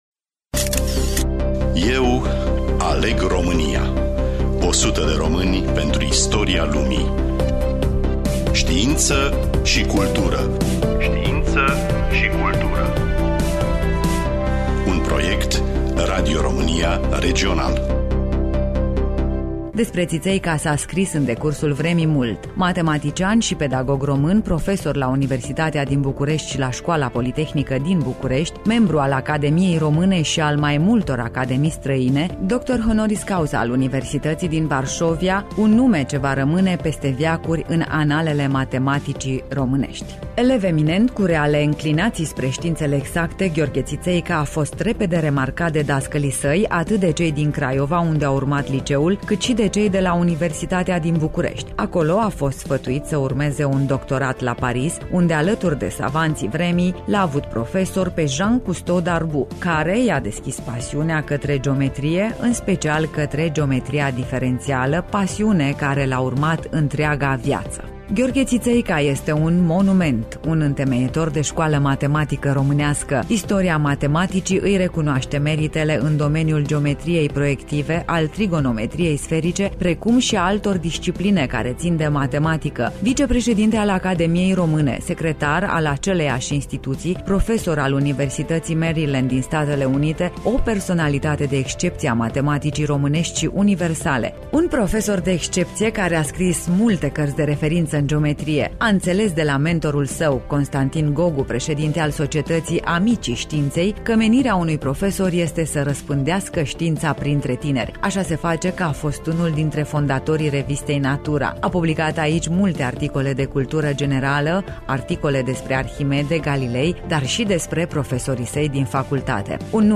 Studioul: Radio România Reşiţa